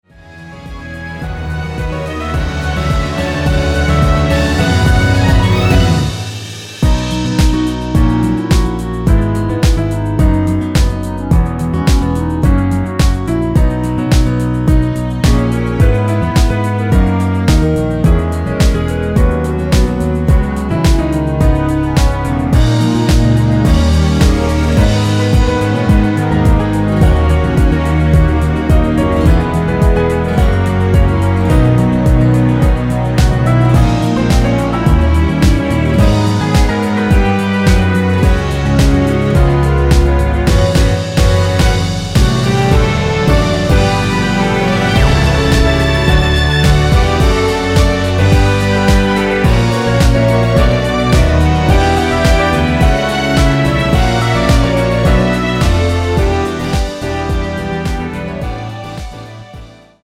원키에서(-2)내린 멜로디 포함된 MR입니다.
◈ 곡명 옆 (-1)은 반음 내림, (+1)은 반음 올림 입니다.
멜로디 MR이라고 합니다.
앞부분30초, 뒷부분30초씩 편집해서 올려 드리고 있습니다.